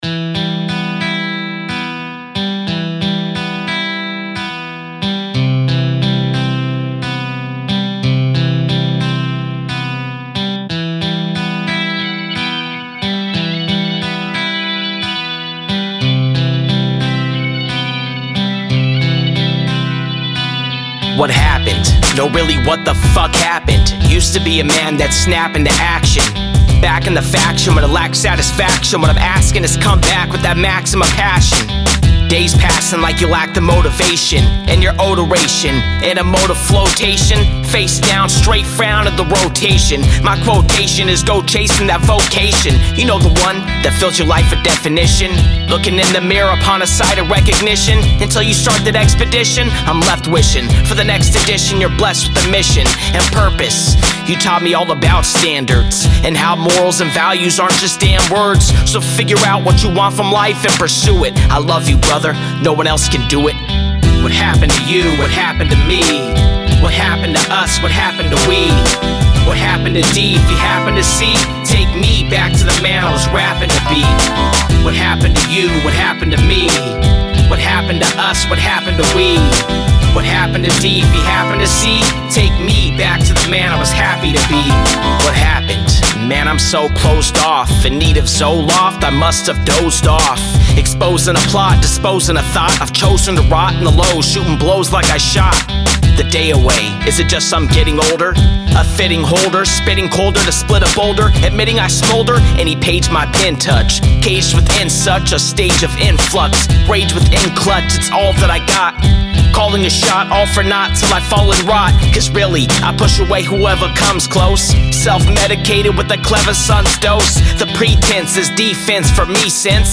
Recorded at AD1 Studios